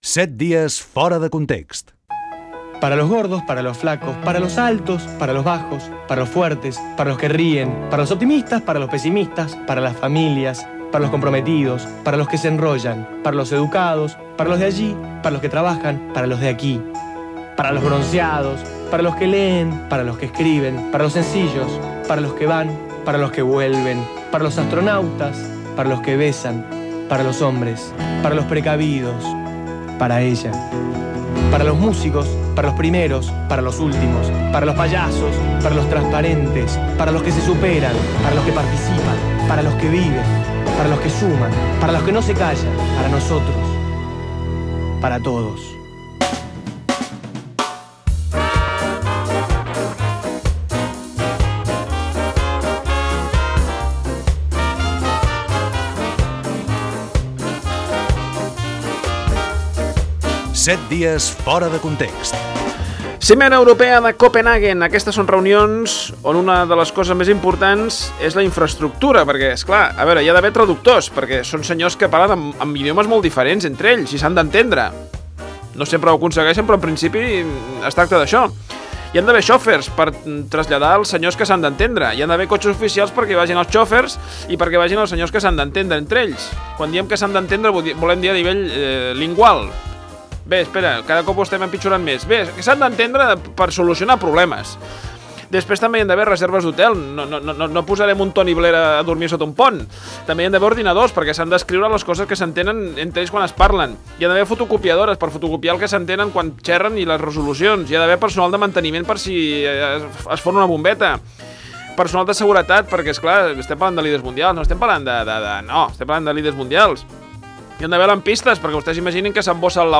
Careta del programa, la cimera de Copenhague , identificació, "Els titulars de la setmana": mocadorada al president del F.C. Barcelona Joan Gaspar, el cas del petrolier Prestige, etc.
Entreteniment
FM